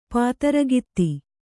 ♪ pātaragitti